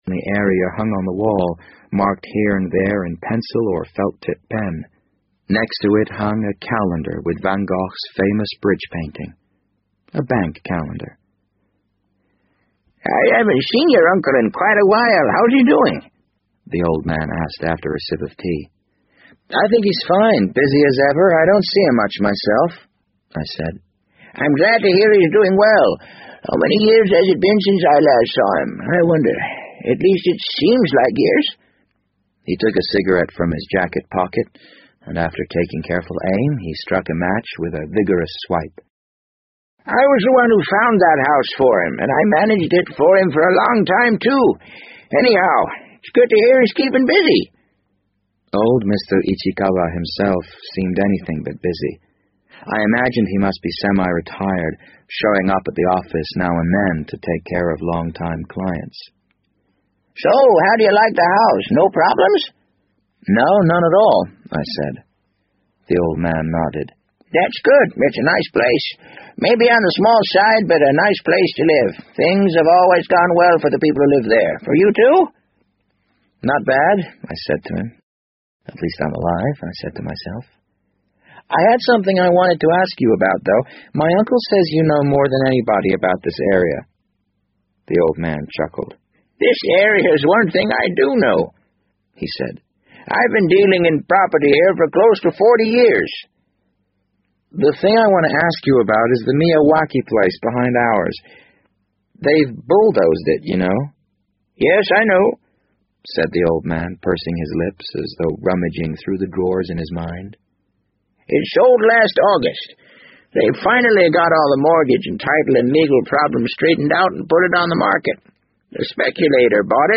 BBC英文广播剧在线听 The Wind Up Bird 009 - 9 听力文件下载—在线英语听力室